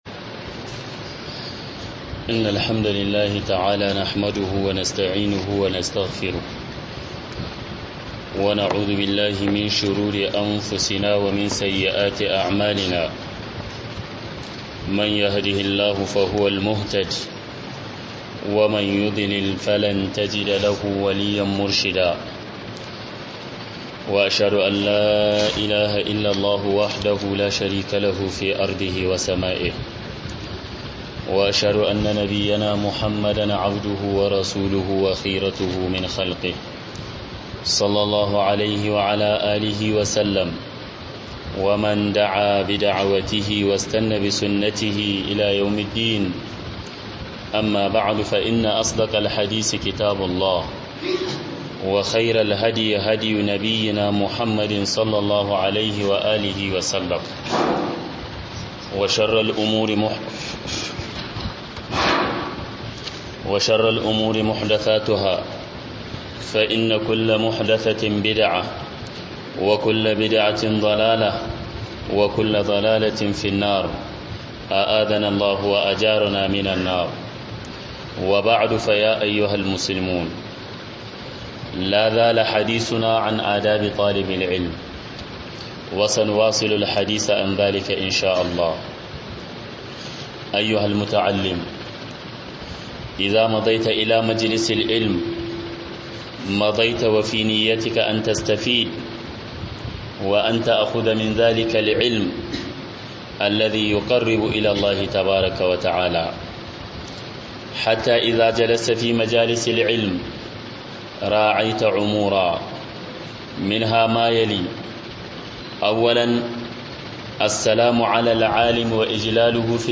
HUDUBA 2019-10-26-at-11.13.37